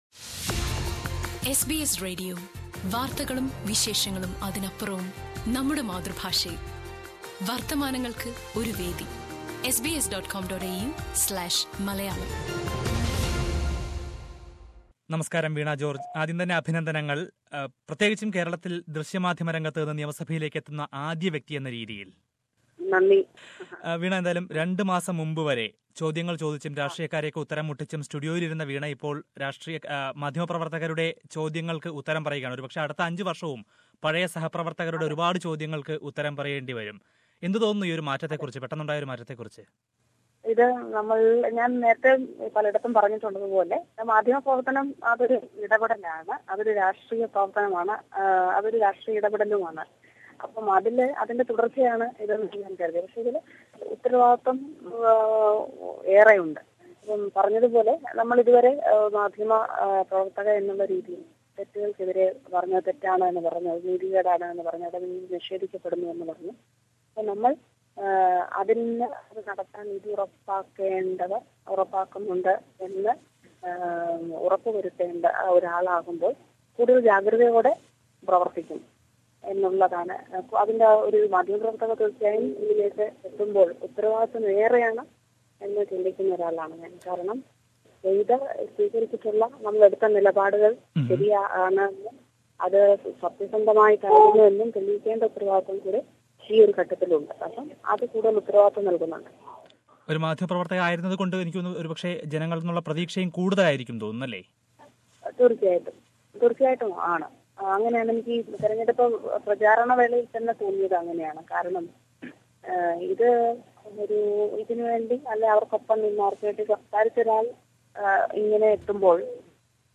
Interview: Veena George
Veena George is perhaps one of the most talked about candidates in the Kerala assembly election. After the victory, she talks to SBS Malayalam Radio